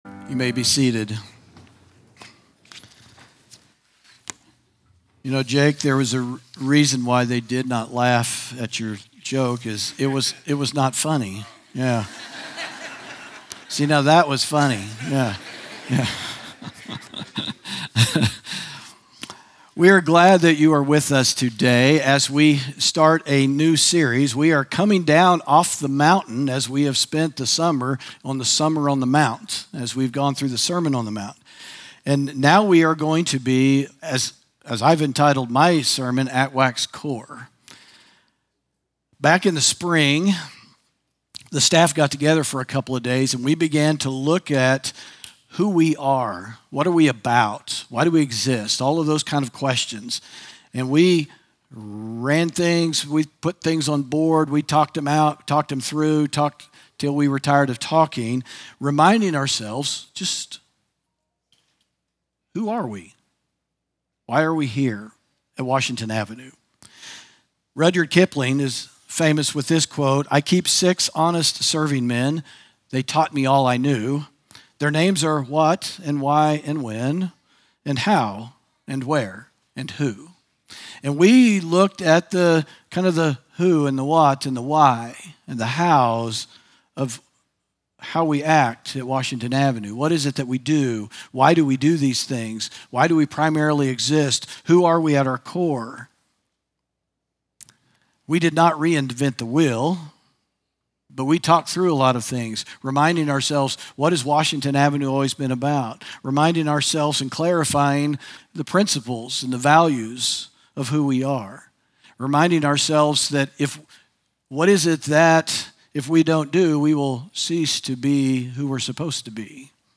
sermon audio 0907.mp3